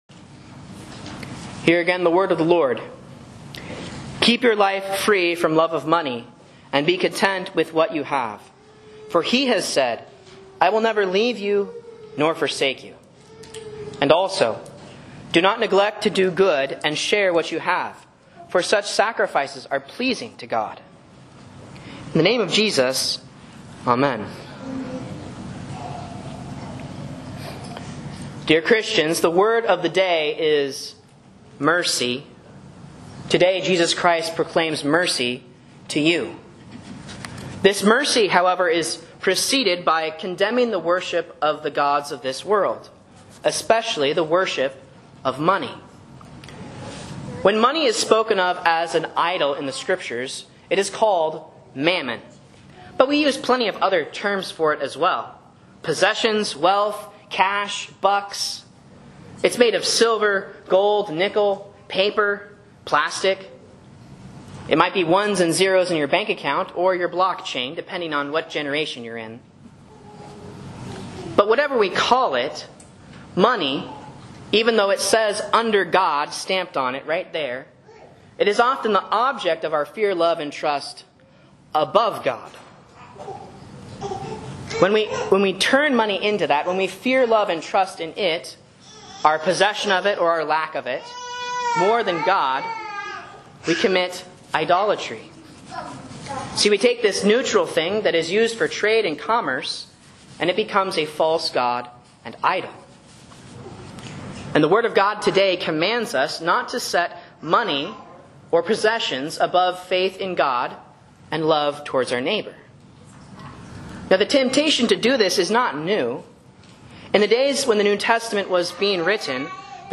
Sermons and Lessons from Faith Lutheran Church, Rogue River, OR
A Sermon on Mercy
A Sermon on Hebrews 13:5, 16 for Proper 17 (C)